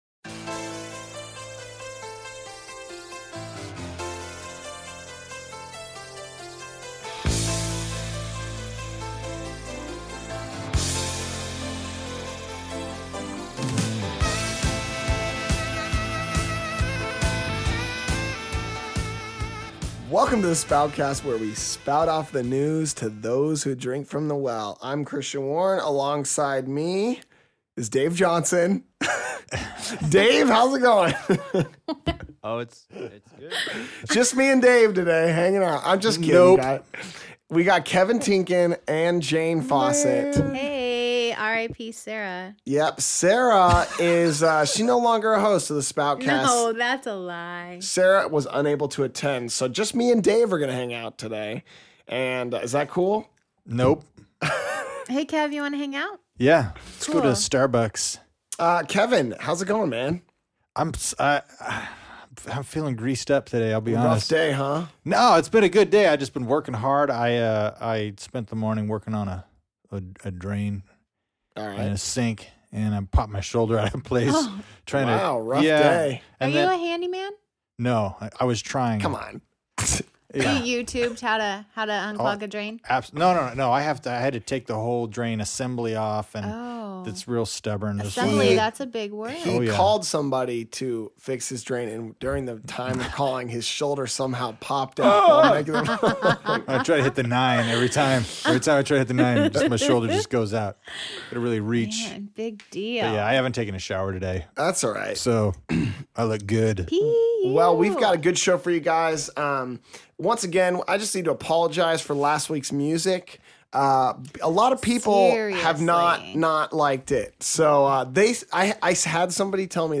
The Spoutcast Crew also discusses their thoughts on the sermon, and how each of them responded to the message.